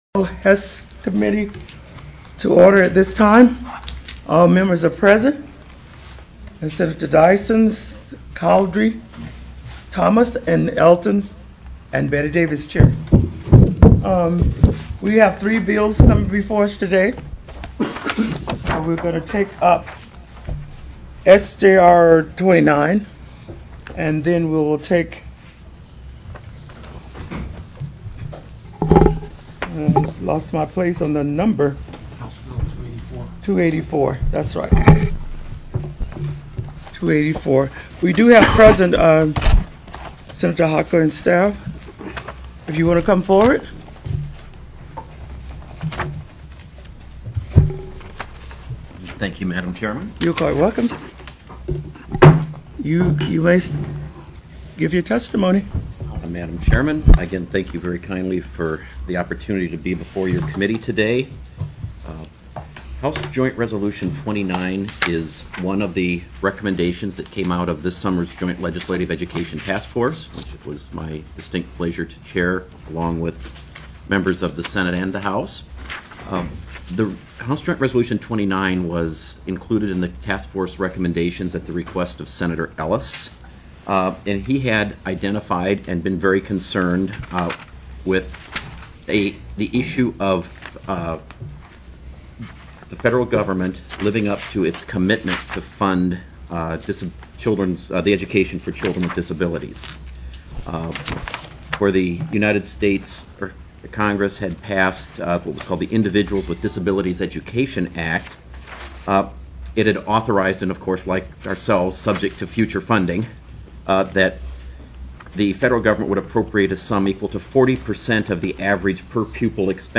03/26/2008 01:30 PM Senate HEALTH, EDUCATION & SOCIAL SERVICES
TELECONFERENCED